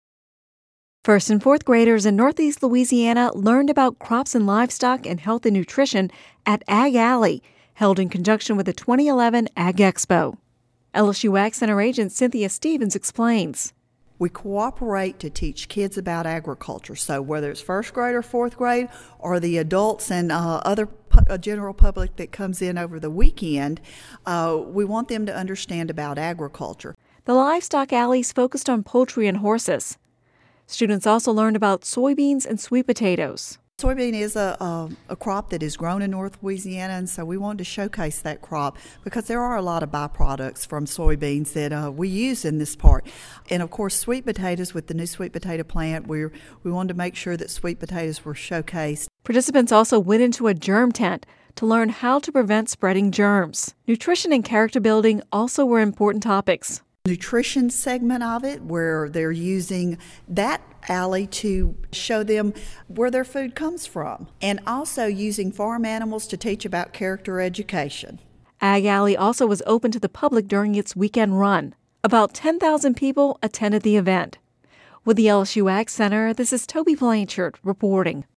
(Radio News 01/31/11) First- and fourth-graders in northeast Louisiana learned about crops and livestock and health and nutrition at Ag Alley, which was held in conjunction with the 2011 Ag Expo.